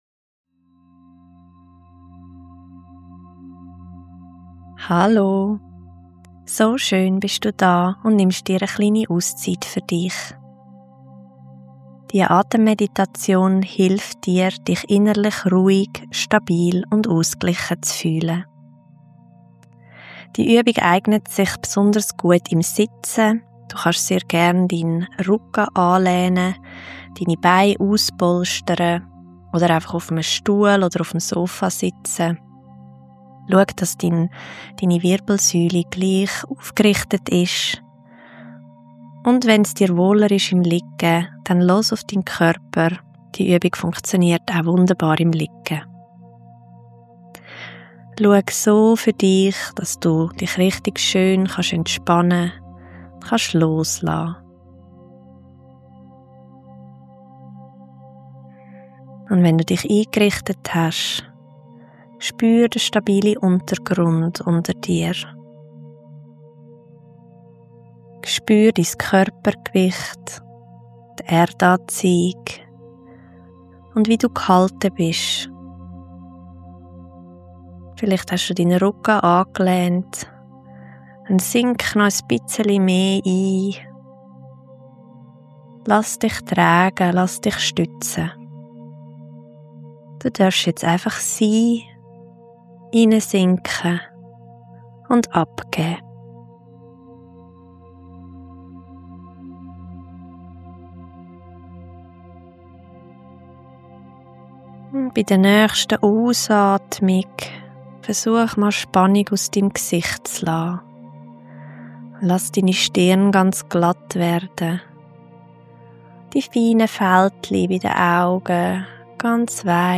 Atemmeditation-fuer-innere-Ruhe-mit-Musik.mp3